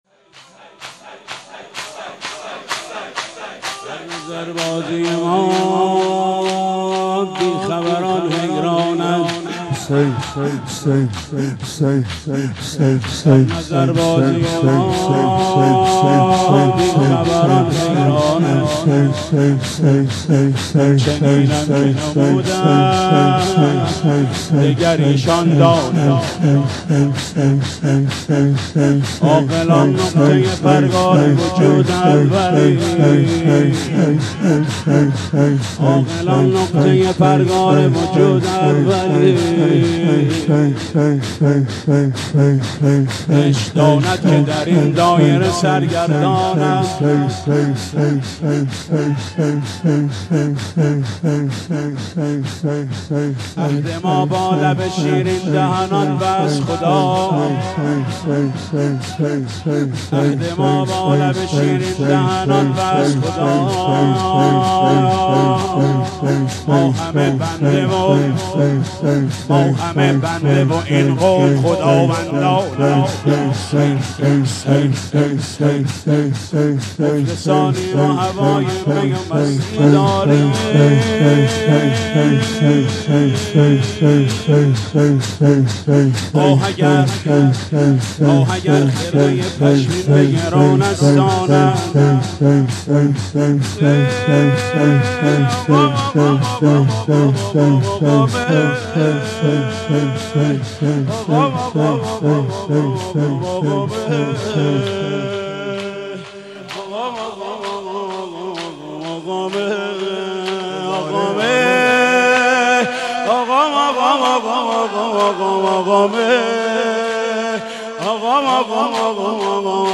با اینکه پیکر پسرش بوریا شود روضه محمود کریمی